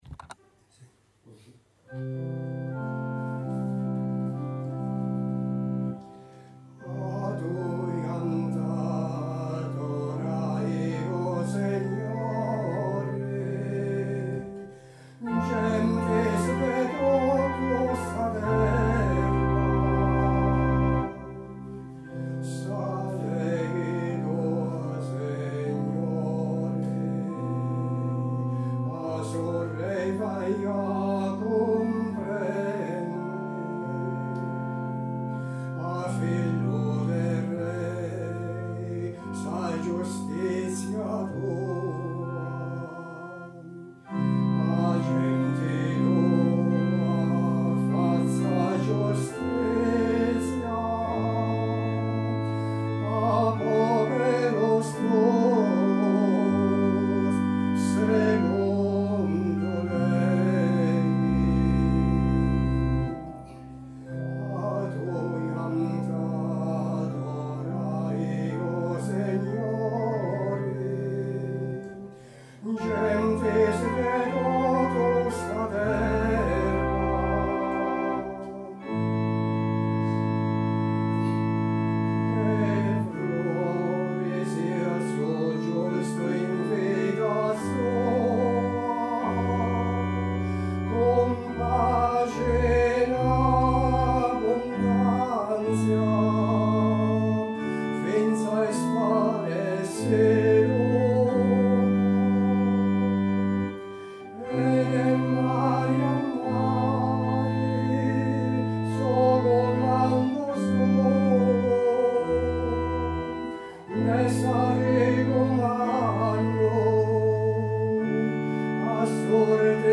Salmu_72_voce_.mp3